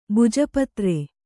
♪ buja patre